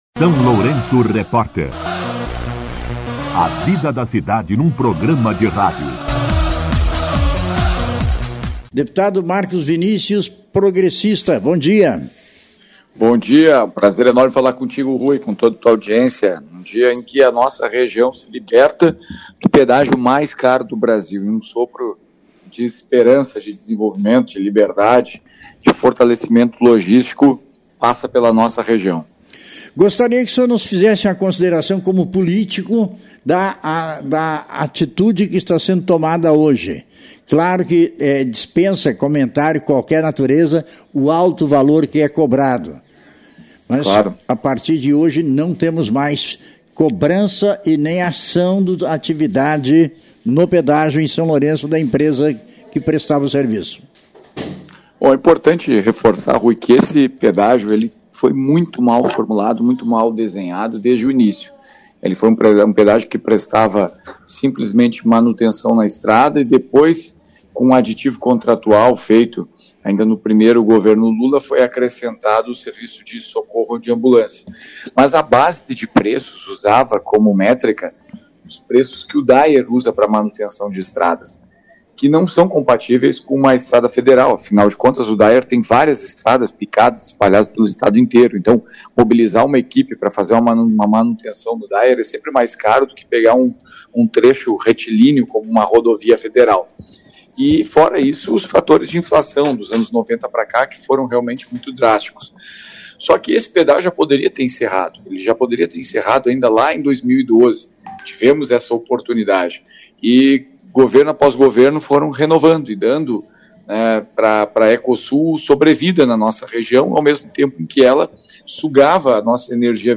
O deputado estadual Marcus Vinícius (PP) concedeu entrevista à SLR Rádio nesta quarta-feira (4), data que marca o fim do contrato dos pedágios no Polo Rodoviário de Pelotas com a Ecovias Sul.
Entrevista com o deputado estadual Marcus Vinícius (PP)